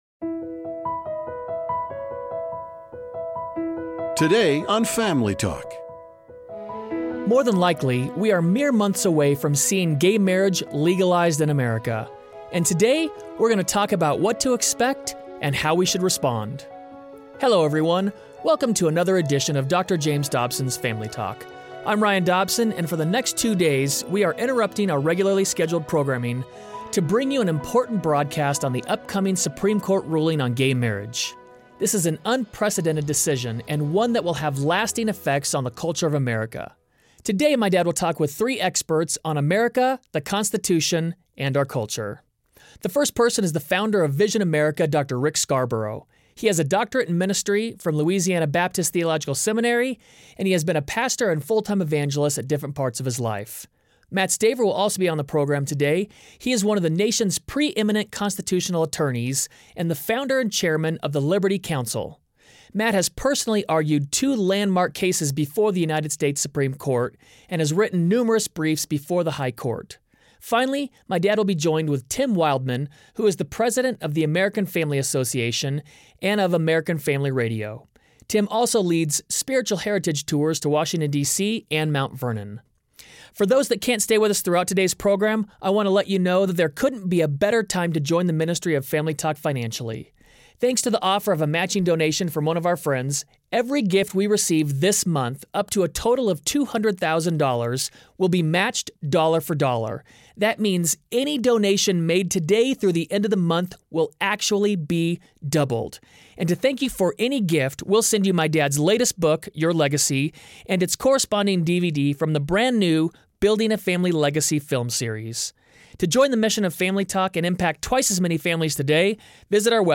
Most likely, we are months away from seeing gay marriage legalized in America. On the next edition of Family Talk, Dr. James Dobson interviews a panel of guests about what to expect and how to respond.